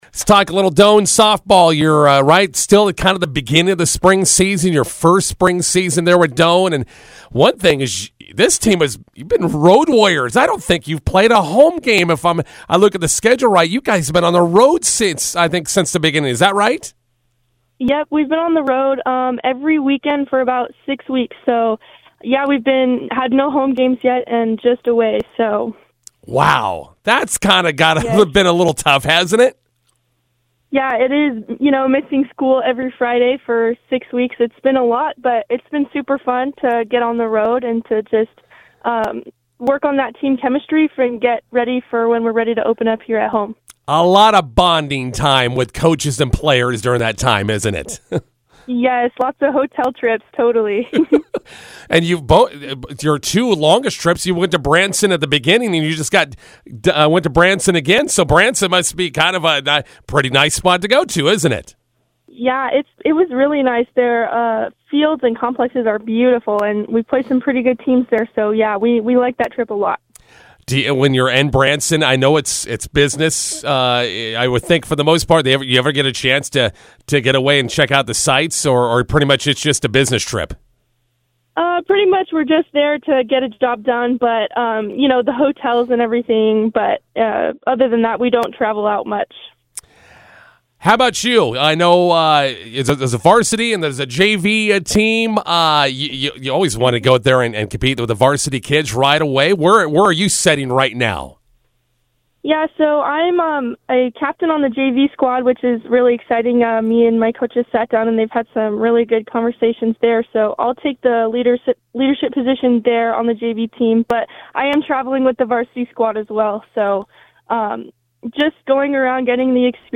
INTERVIEW: Doane spring softball off and running.